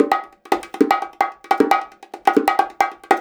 150BONGO 3.wav